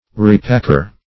Repacker \Re*pack"er\ (-?r), n. One who repacks.